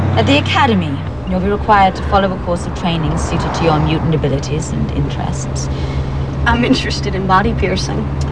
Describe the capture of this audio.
Sounds From the Generation X telefilm